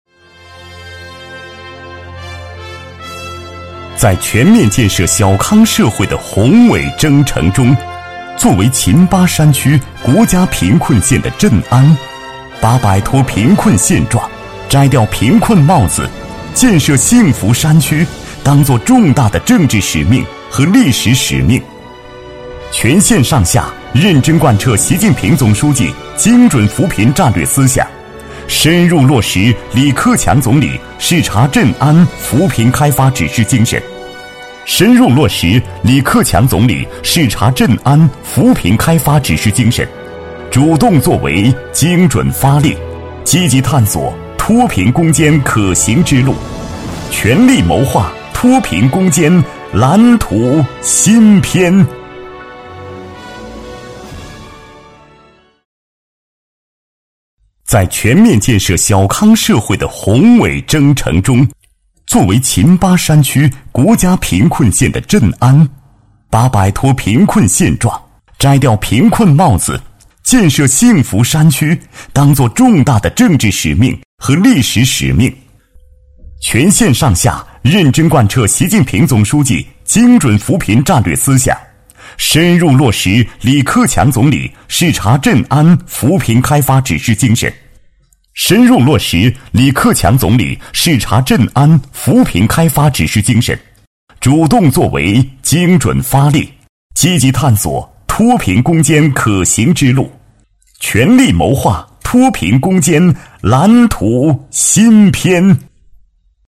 政府专题配音